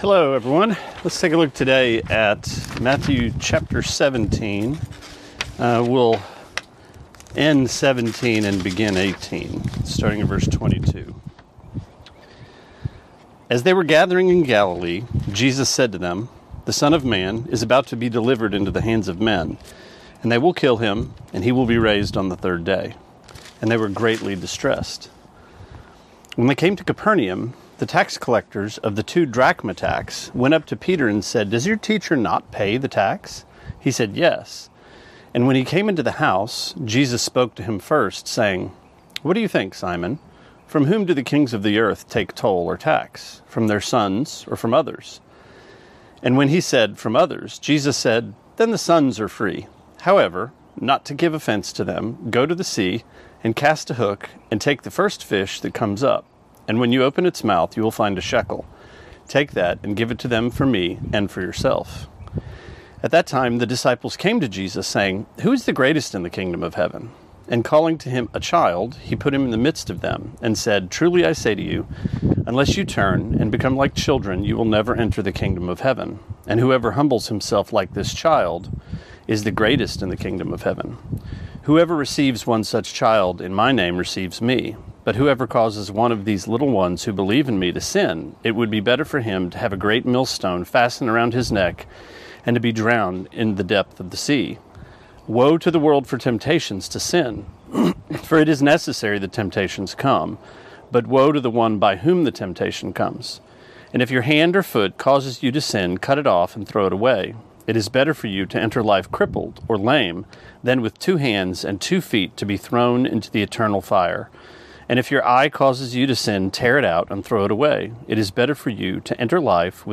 Sermonette 3/9-10: Matthew 17:22-18:9: Little Ones, Great Ones